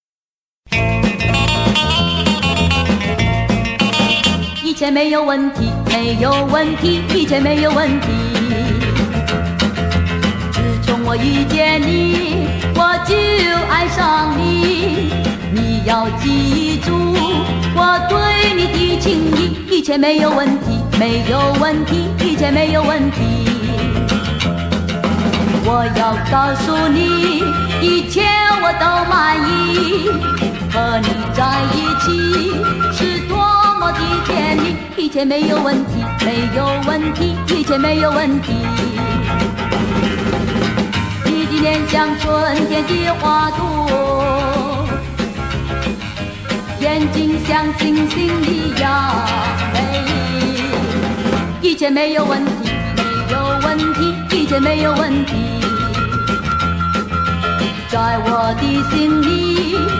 Genre: Rock, Pop
Style: Pop Rock, Cantopop, Hokkien Pop, Mandopop